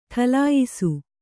♪ ṭhalāyisu